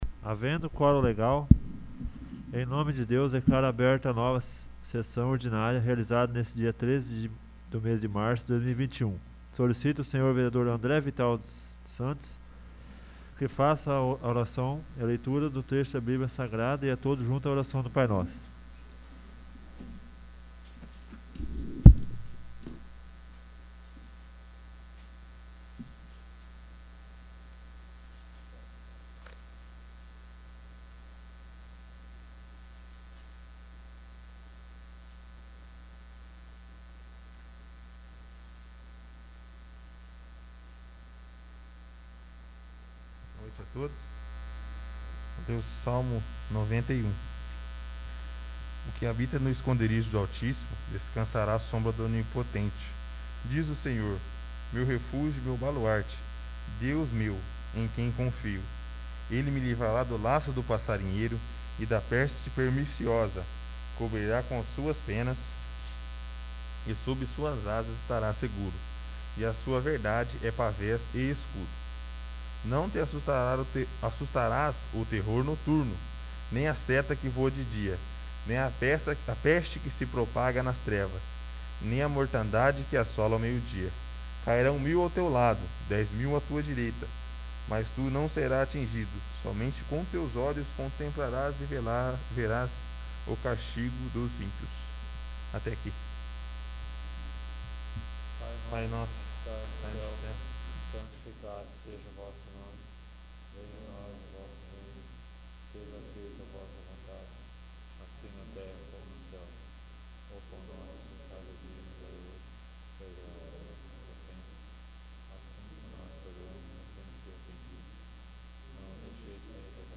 9º. Sessão Ordinária